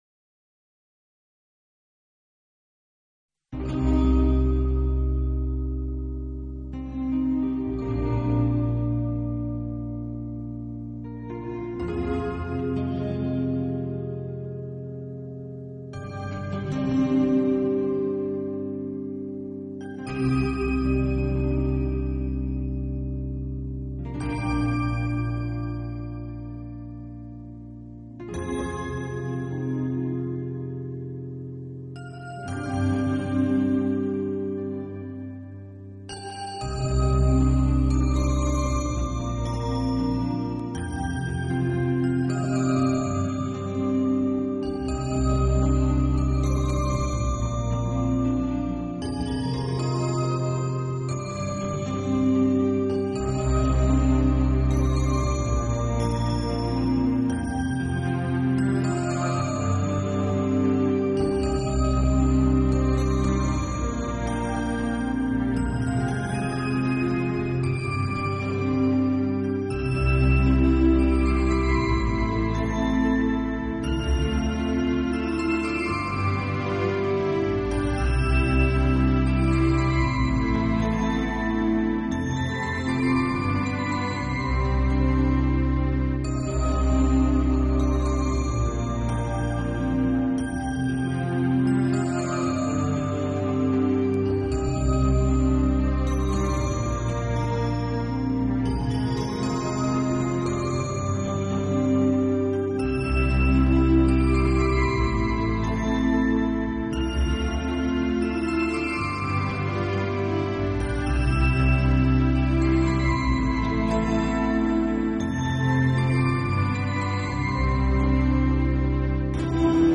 器配置，使每首曲子都呈现出清新的自然气息。